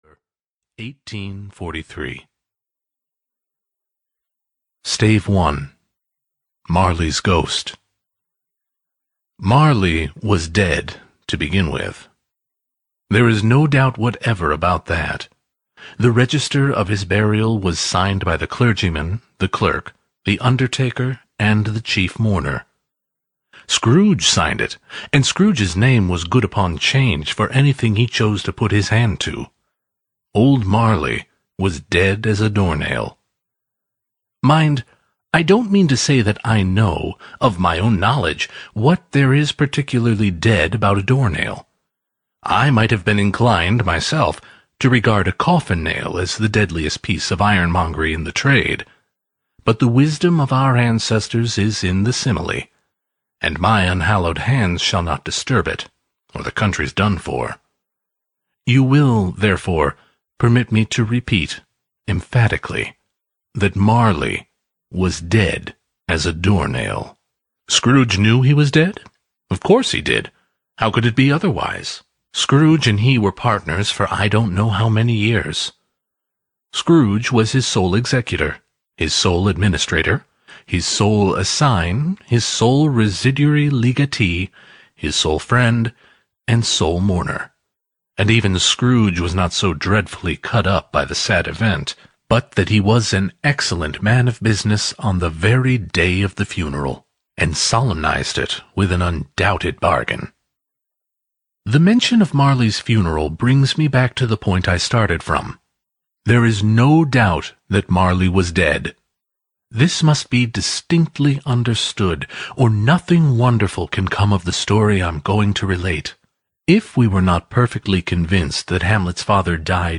A Christmas Carol (EN) audiokniha
Ukázka z knihy